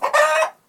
angryloudcluck1.wav